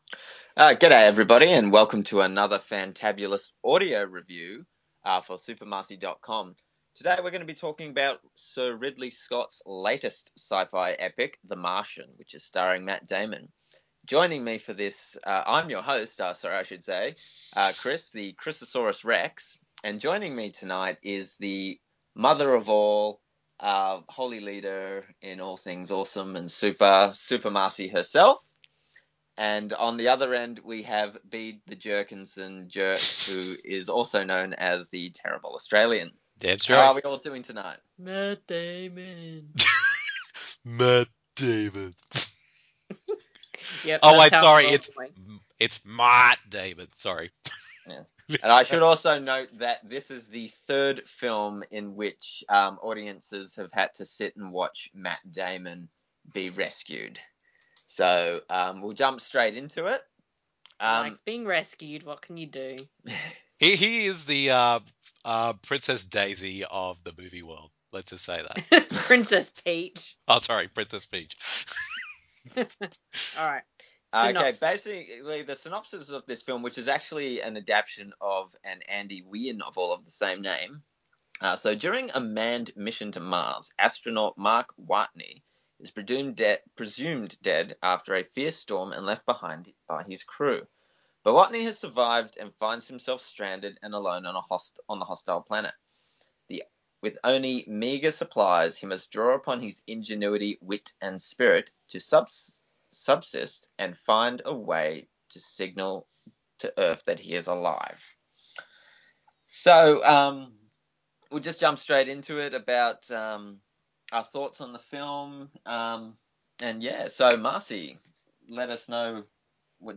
The following review in an audio format, as a back and forth discussion between the three of us.